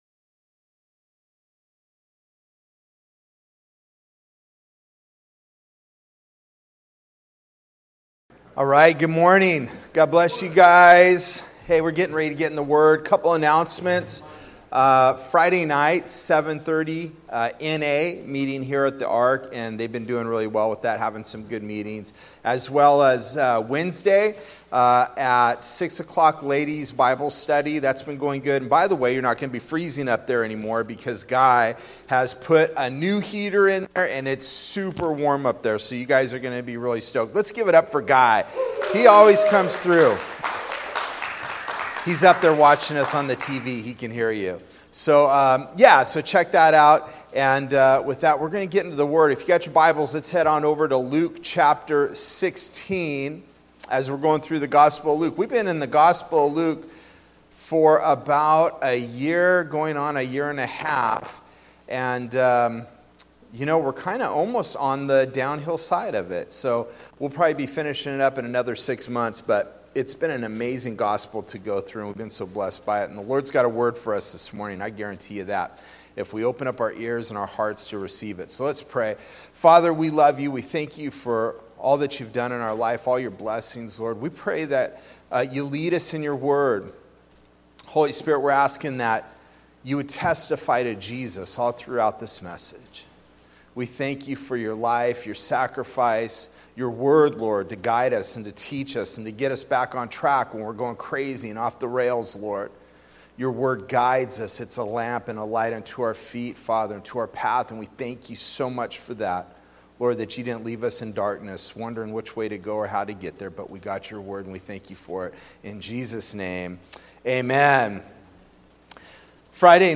Sermons Archive - Page 19 of 44 - Ark Bible Church